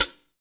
HAT FUZN 0RR.wav